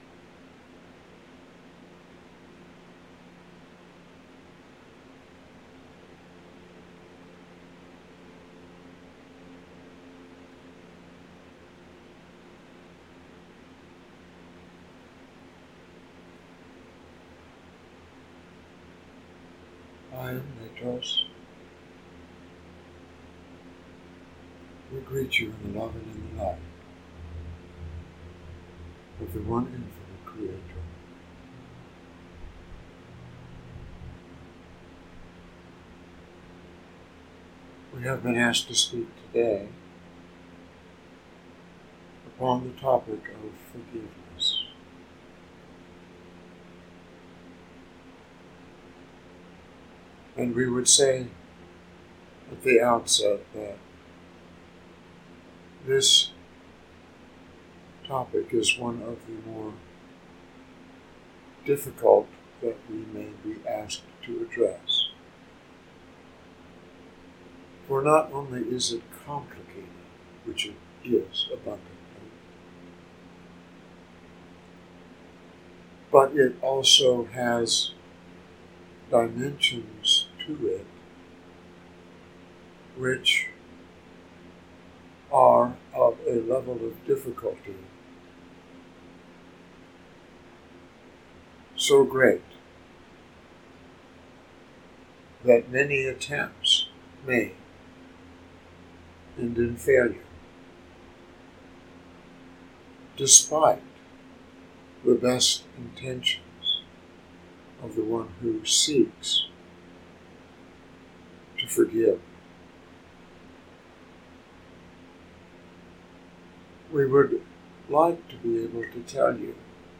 Inspirational messages from the Confederation of Planets in Service to the One Infinite Creator